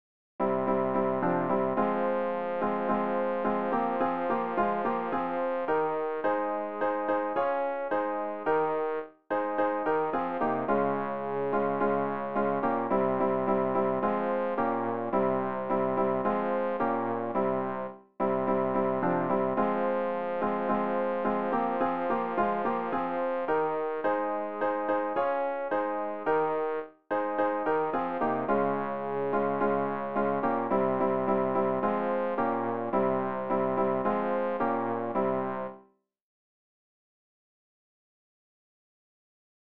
rg-705-dans-nos-obscurites-bass.mp3